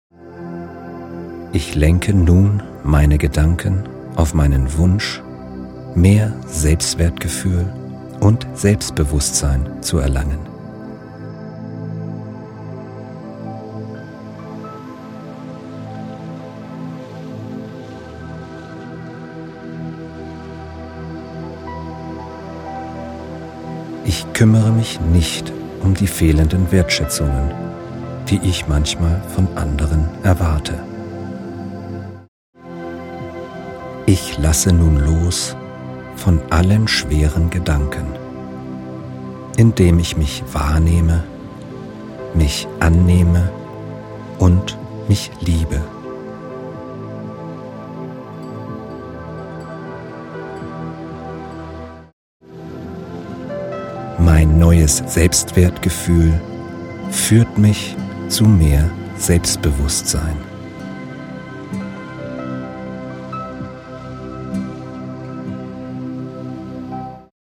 In der öfteren Anwendung werden sich diese Affirmationen setzen und durch die spezielle stimulierende Hintergrundmusik in Ihnen firmieren.
Dem einen fällt die Entspannung bei einer tieferen männlichen Version leichter, den anderen inspiriert die weibliche ruhige Stimmlage.
Weibliche Stimme   15:13 min